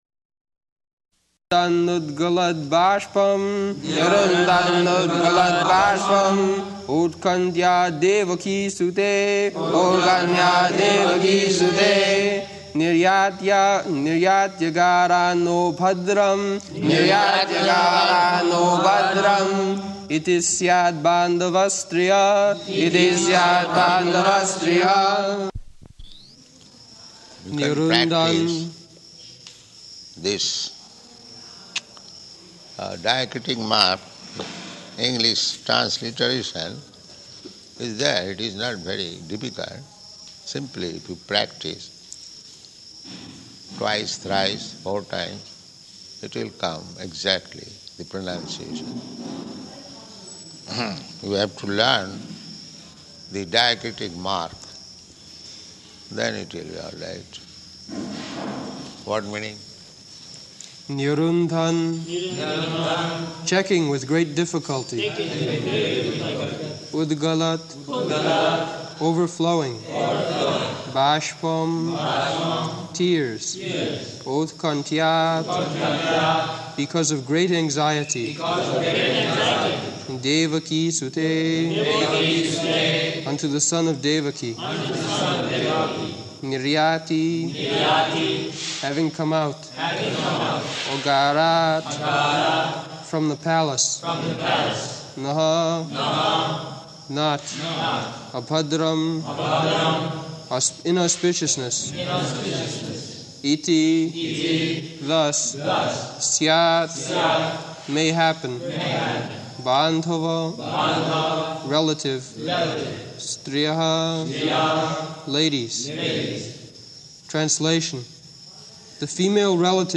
Location: Māyāpur
[Prabhupāda and devotees repeat]